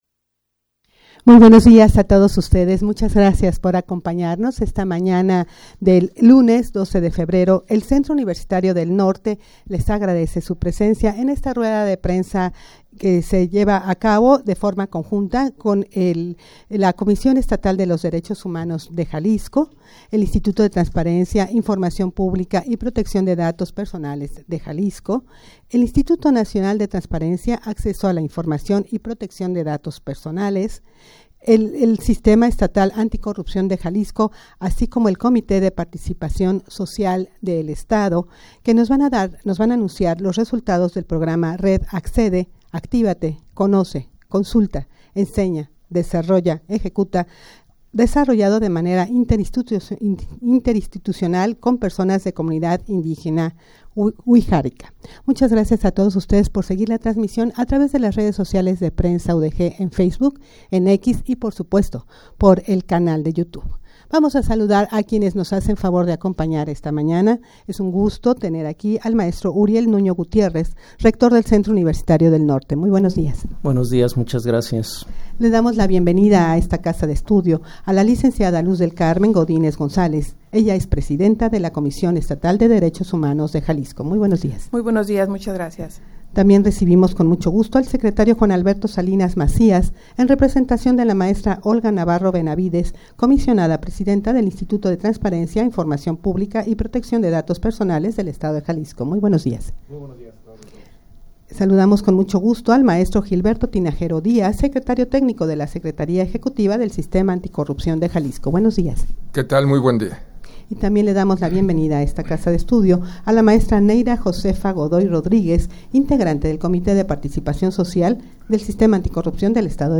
Audio de la Rueda de Prensa
rueda-de-prensa-para-anunciar-los-resultados-del-programa-red-accede.mp3